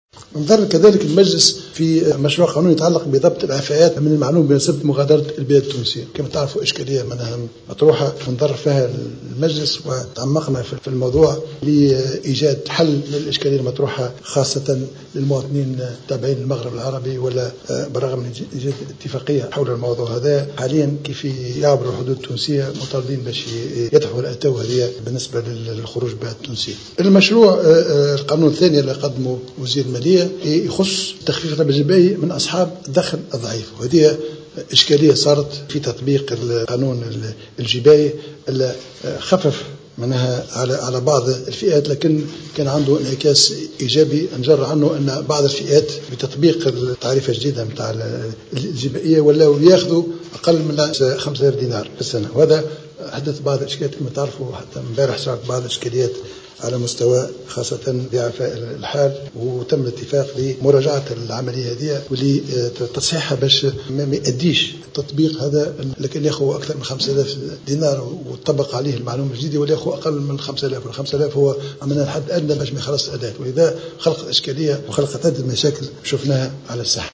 أكد رئيس الحكومة الحبيب الصيد في تصريح اعلامي عقب مجلس وزاري أشرف عليه رئيس الجمهورية اليوم الأربعاء 18 فيفري 2015 أن المجلس نظر في مشروع قانون يتعلق بضبط الإعفاءات من معلوم مغادرة البلاد التونسية.